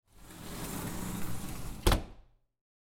جلوه های صوتی
دانلود صدای قطار 2 از ساعد نیوز با لینک مستقیم و کیفیت بالا